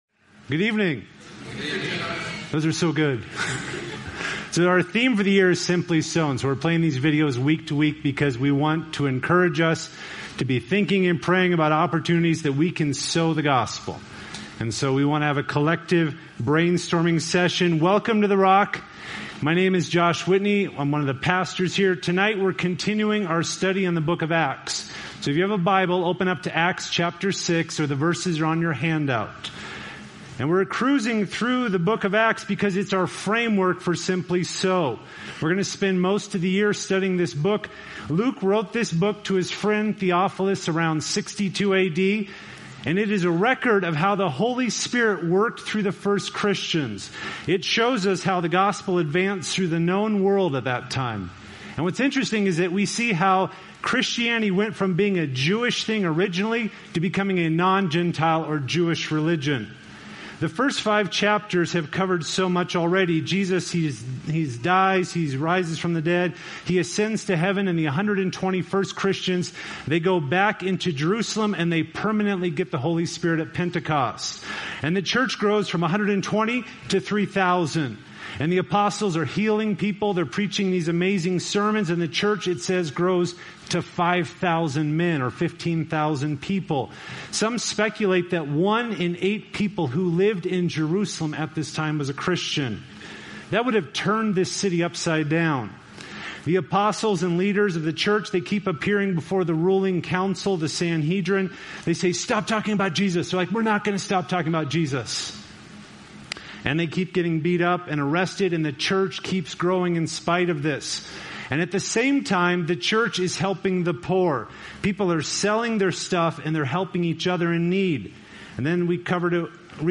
A message from the series "Healthy Living." Conflict is all around us.